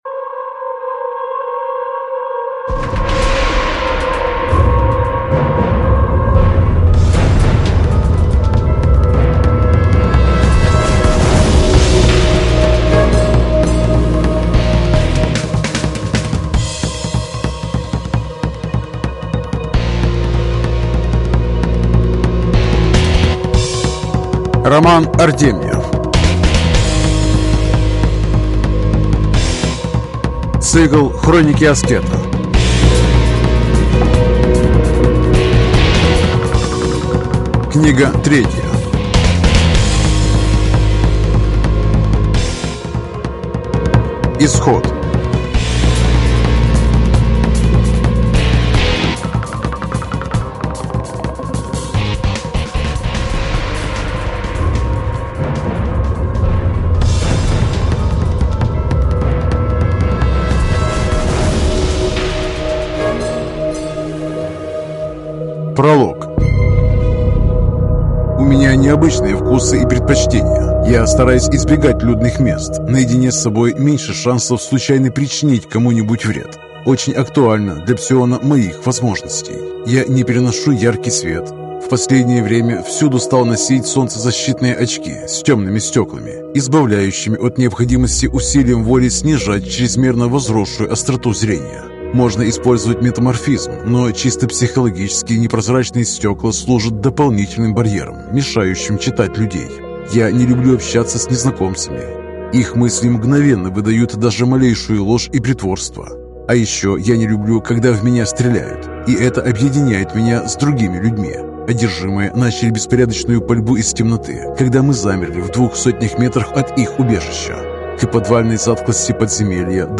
Аудиокнига Исход | Библиотека аудиокниг